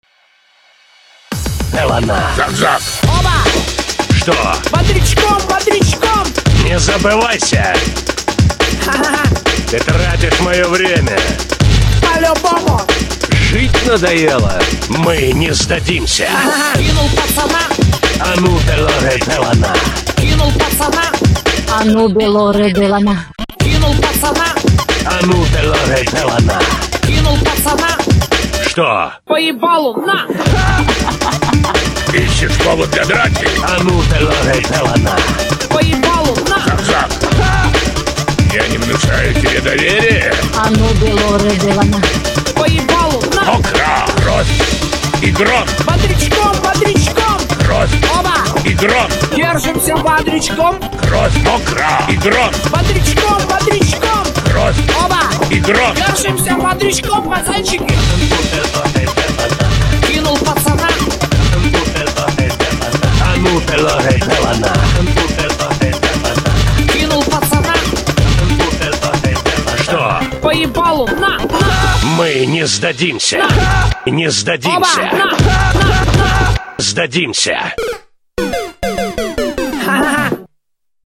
Стили: Breaks, Electro
BPM: 140
Аудиоряд, сотканный из реплик персонажей WarCraft III